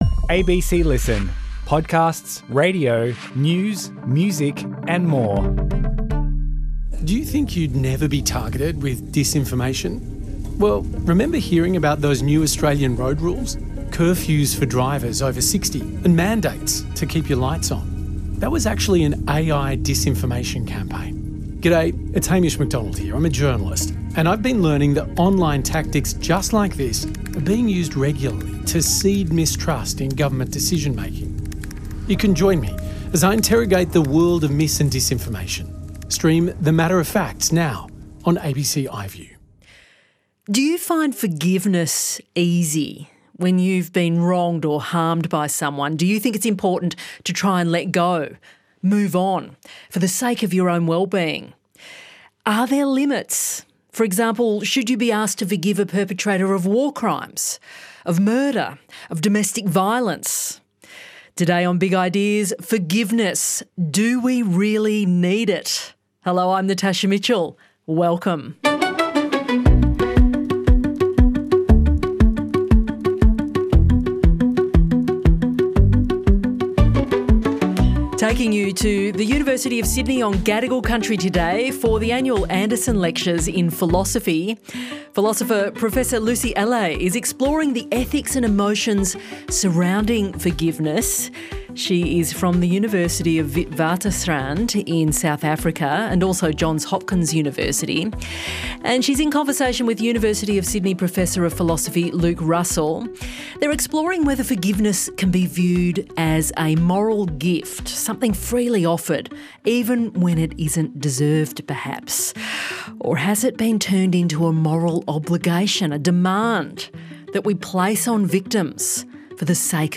2025 Anderson Fellows Lecture — Forgiveness: Do We Really Need it? presented by the University of Sydney